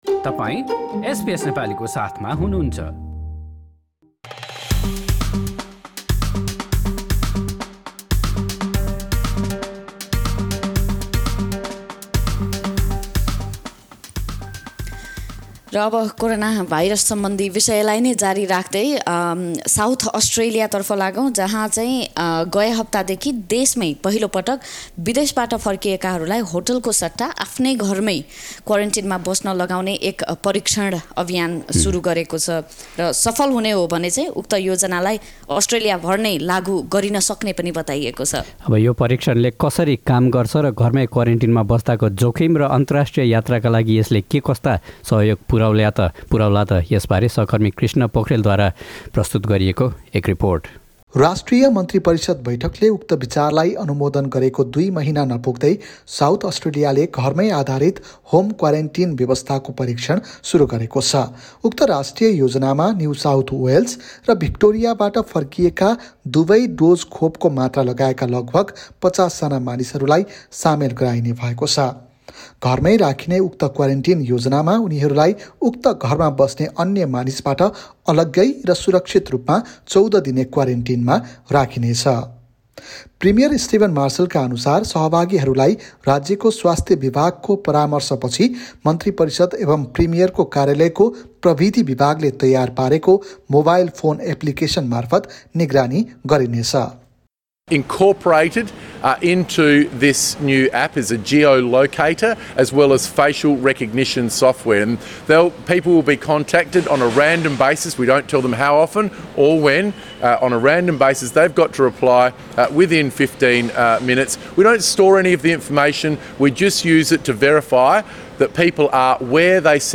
साउथ अस्ट्रेलियाले गएको हप्तादेखि देशमै पहिलो पटक - विदेशबाट फर्किएकाहरूलाई होटेलको सट्टा आफ्नो घरमै क्वारेन्टिनमा बस्न लगाउने एक परीक्षण अभियान सुरु गरेको छ। सफल भएमा भने उक्त योजनालाई अस्ट्रेलिया भर नै लागु गरिन सक्ने बताइँदै गर्दा यो परीक्षणले कसरी काम गर्ने छ, घरमै क्वारेन्टिनमा बस्दाको जोखिम र अन्तर्राष्ट्रिय यात्राका लागि यसले के कस्ता सहयोग पुर्‍याउला त भन्ने बारेमा एक रिपोर्ट।